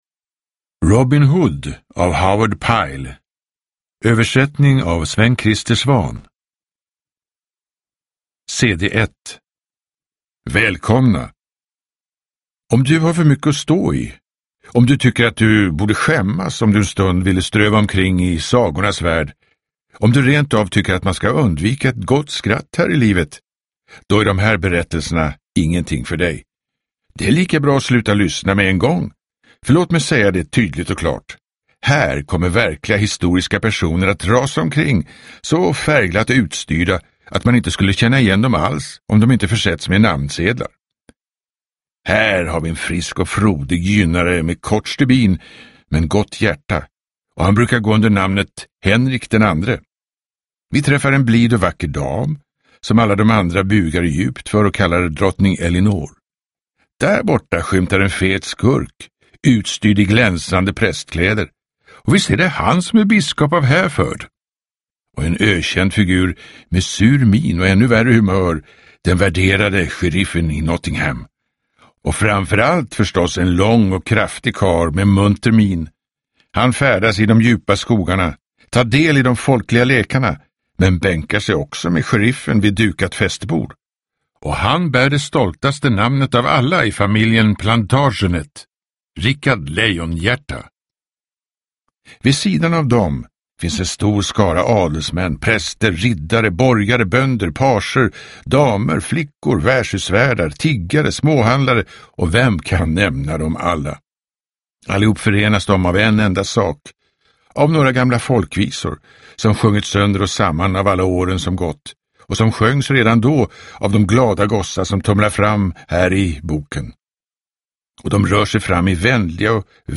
Berättare